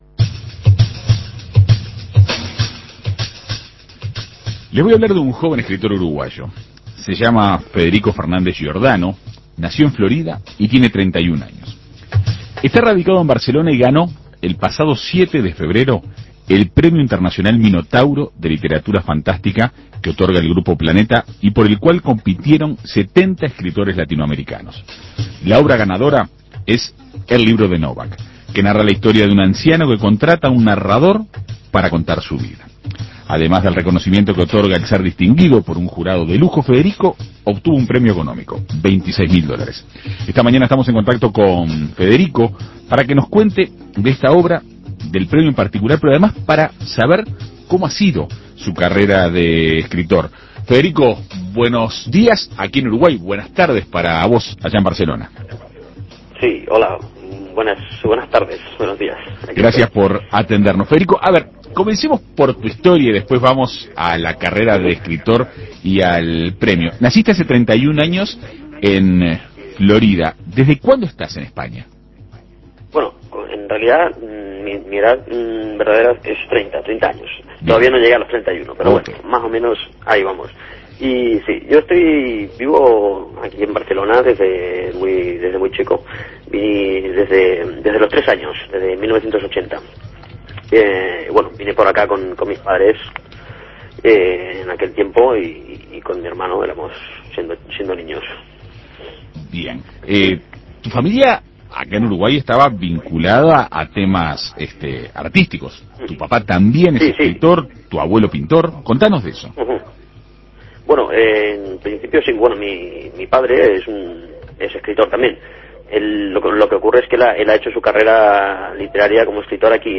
En Perspectiva Segunda Mañana dialogó con el galardonado escritor uruguayo.